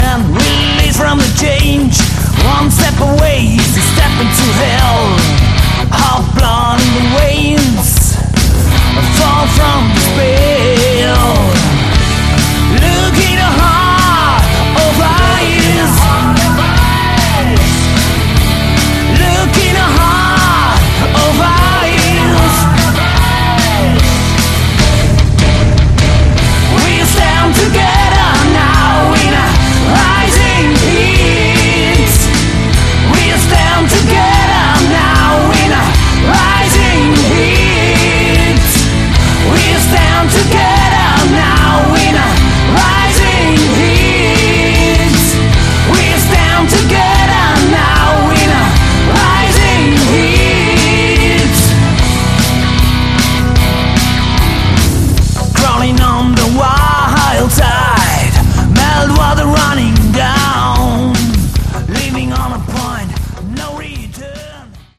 Category: melodic hard rock
Vocals
Guitars
Bass
Keyboards
Drums